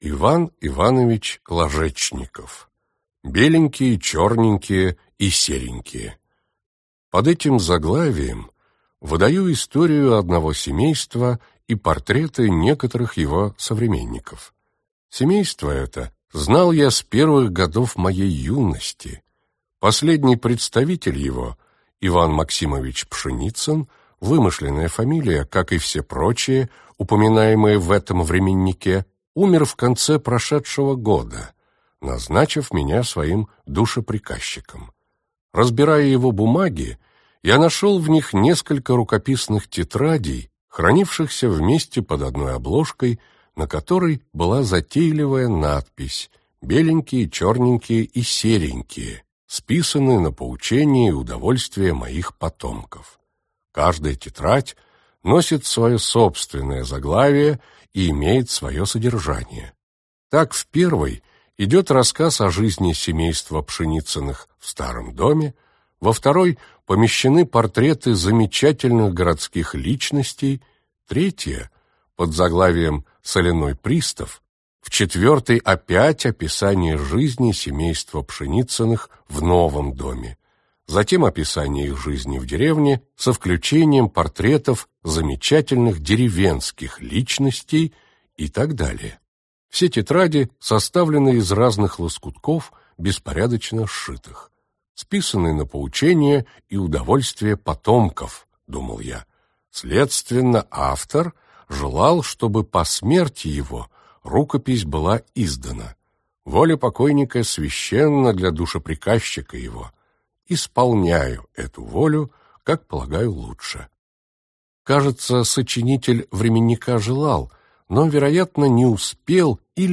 Аудиокнига Беленькие, черненькие и серенькие | Библиотека аудиокниг